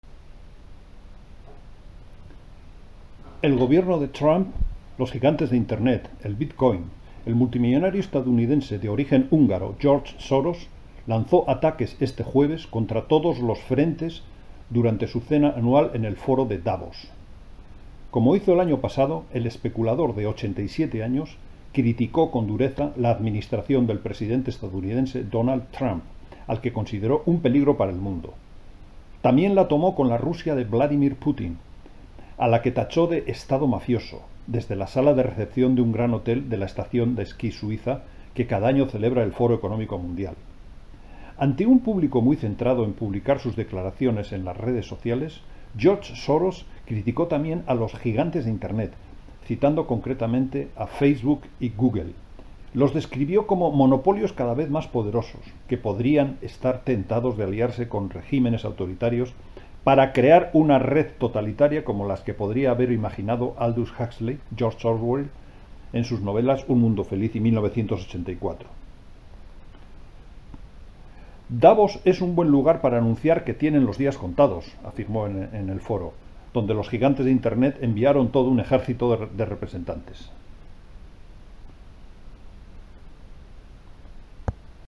Locutor en español documentales. Spanish speaker for documentaries. Spanischer Sprecher für Dokumentarfilme.
Kein Dialekt
Sprechprobe: Industrie (Muttersprache):